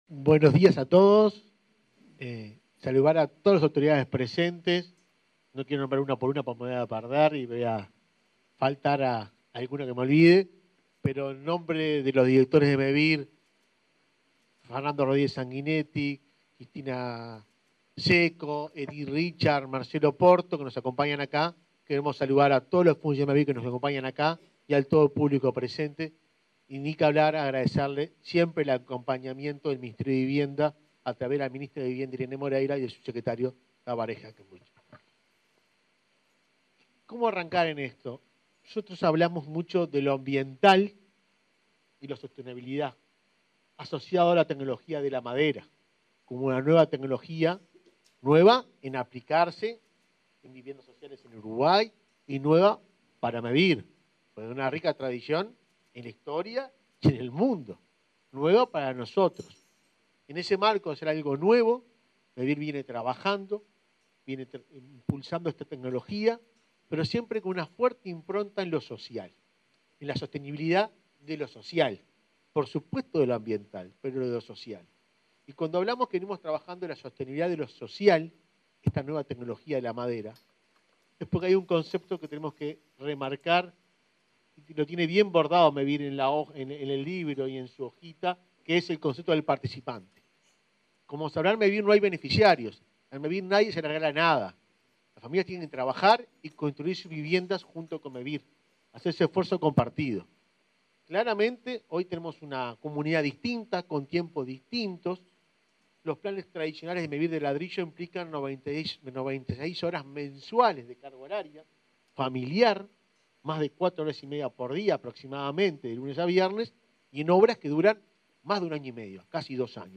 Palabras de autoridades en lanzamiento de Mevir